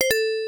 Sonic Alert Tones
Sonic Spinning Sign Sound (I use it for message alerts)
sonicspinner.wav